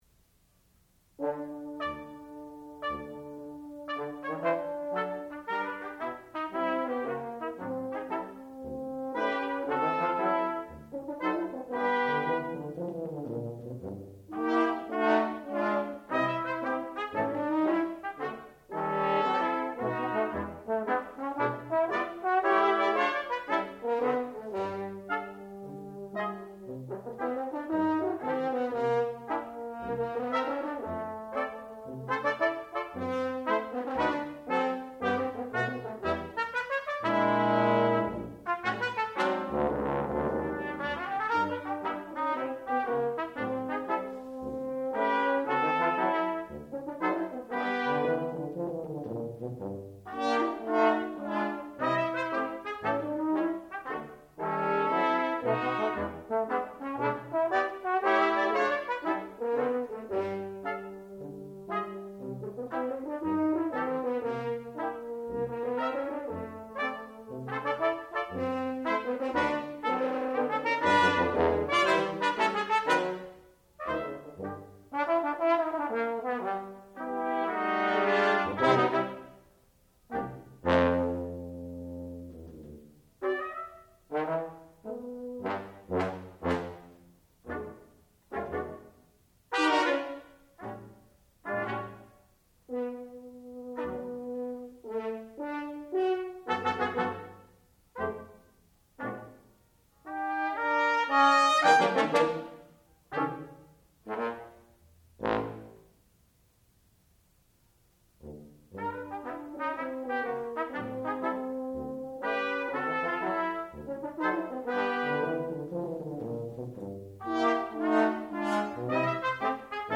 sound recording-musical
classical music
trumpet
tuba